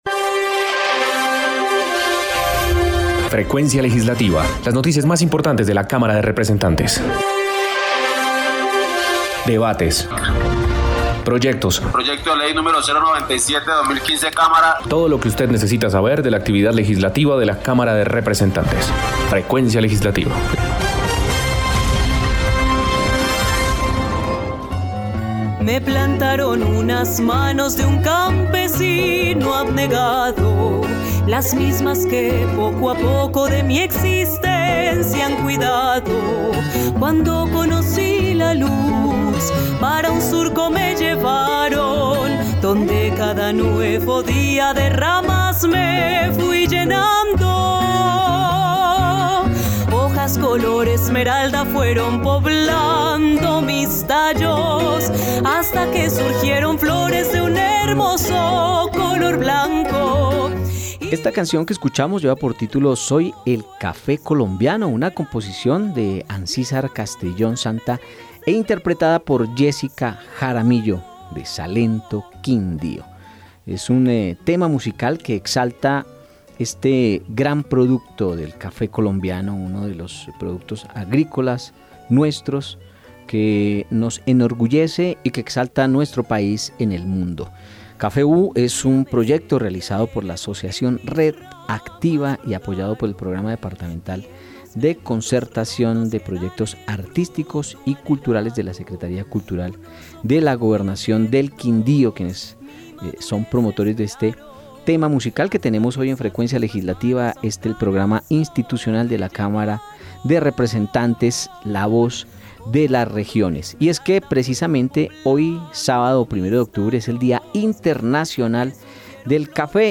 Programa Radial Frecuencia Legislativa Sábado 1 de Octubre de 2022